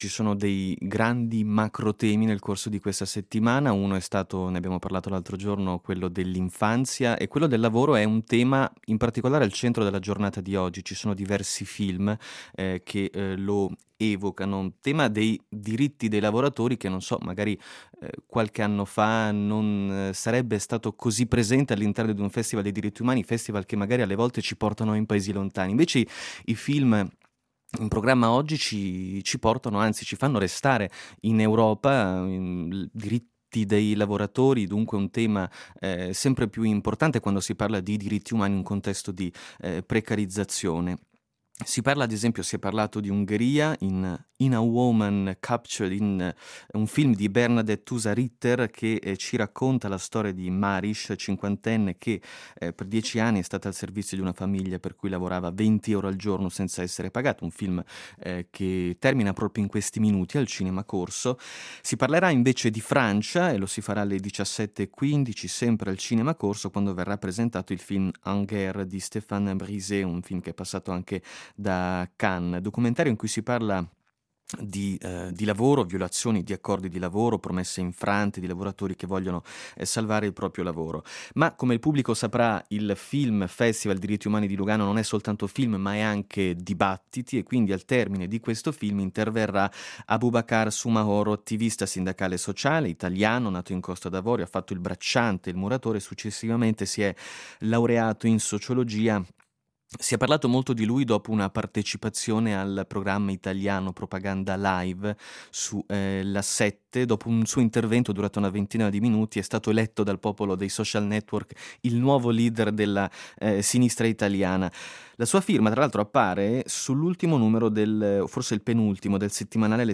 Film Festival Festival Diritti Umani. Intervista a Aboubakar Soumahoro